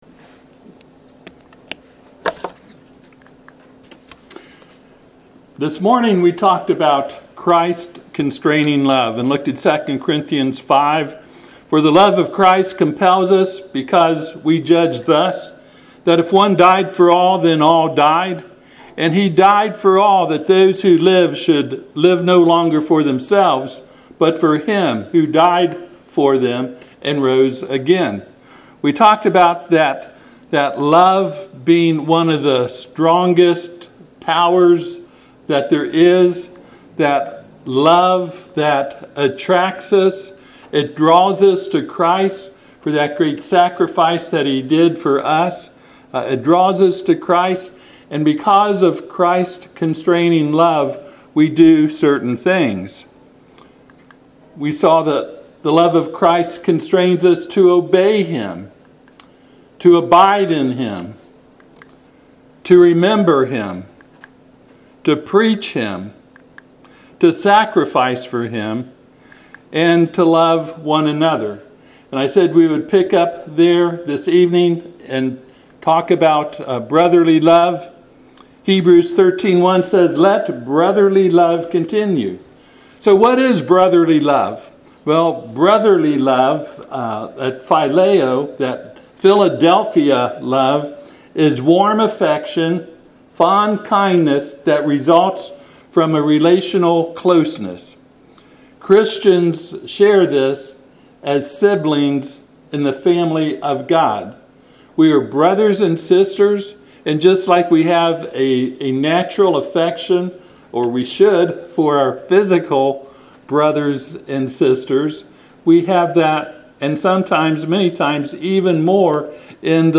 Hebrews 13:1 Service Type: Sunday PM https